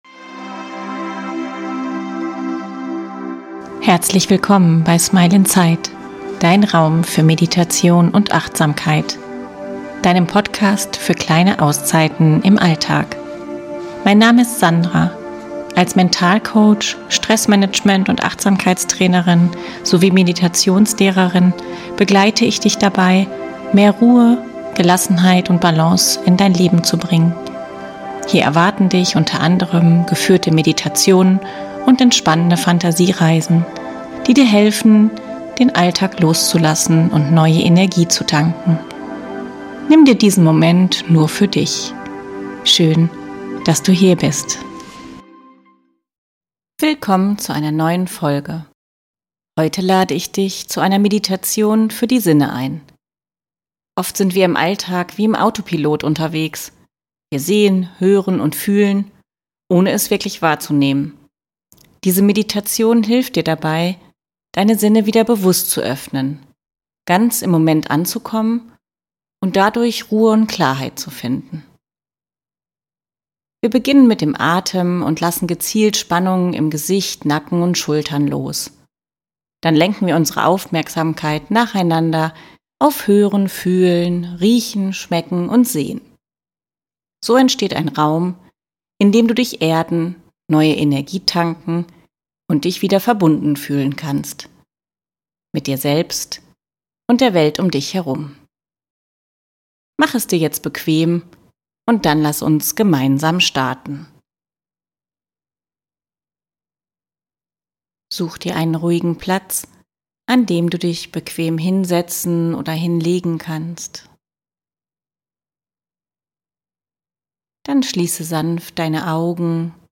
Gemeinsam mit einer sanften Atembeobachtung und kleinen Entspannungsübungen für Kiefer, Stirn, Augen, Schultern und Nacken findest du Schritt für Schritt zurück zu innerer Ruhe. Diese Übung hilft dir, Stress loszulassen, achtsamer zu werden und neue Kraft zu sammeln – eine kleine Auszeit, die dich wieder mit dir selbst verbindet.